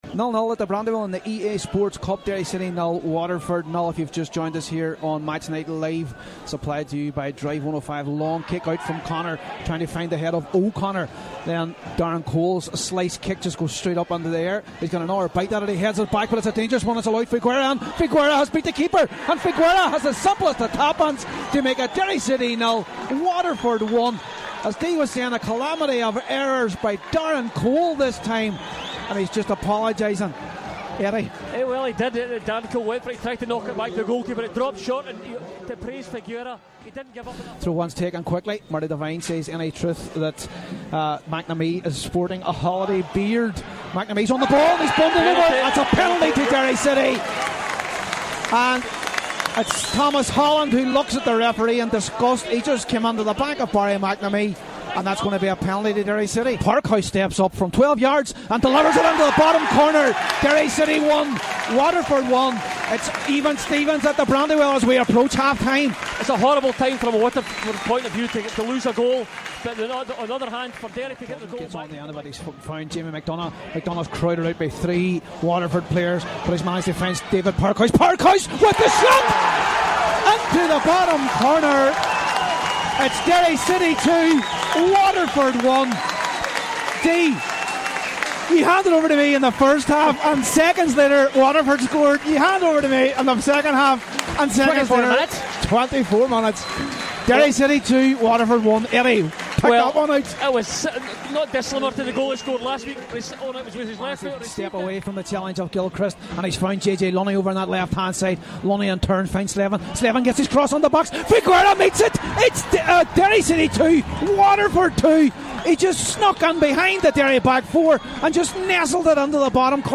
Match highlights and reaction